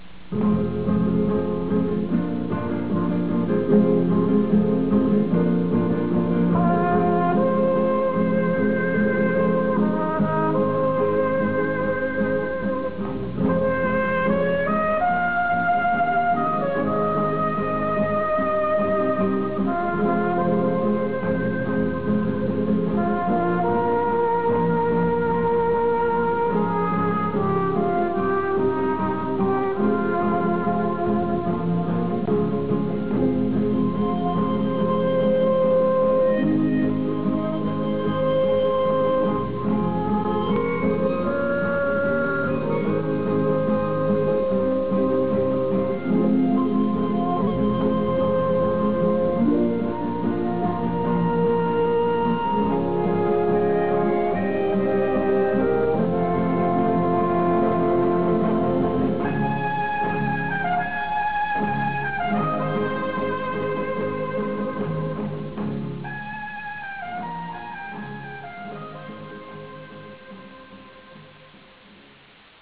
Musica:
Original Track Music